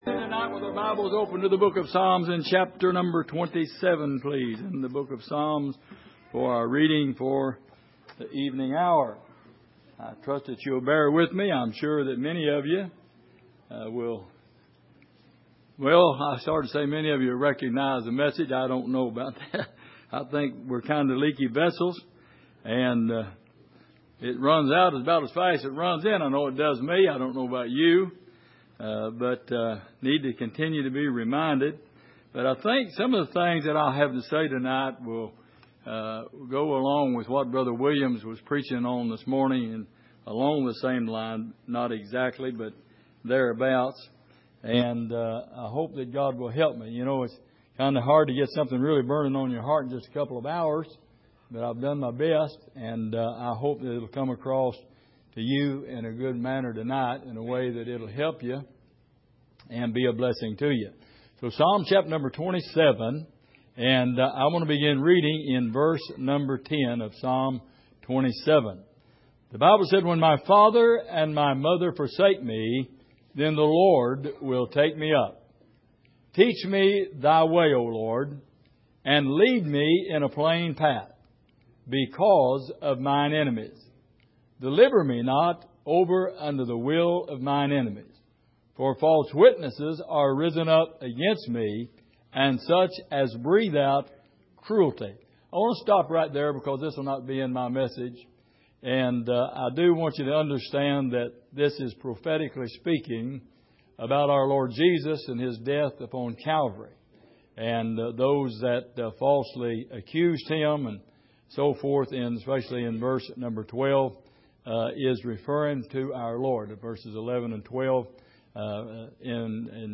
Passage: Psalm 27:13 Service: Sunday Evening